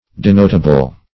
Search Result for " denotable" : The Collaborative International Dictionary of English v.0.48: Denotable \De*not"a*ble\, a. [From Denote .]